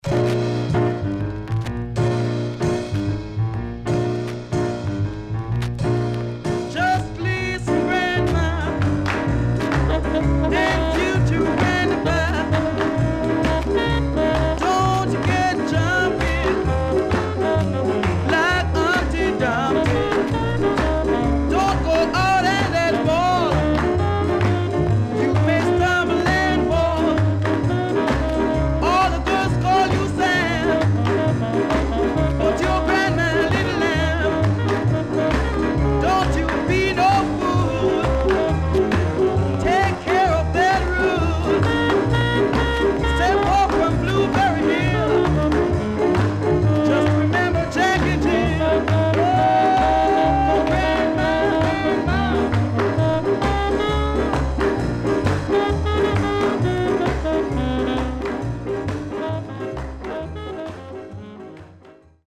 HOME > SKA
Nice Early Ska Vocal
SIDE A:うすいこまかい傷ありますがノイズあまり目立ちません。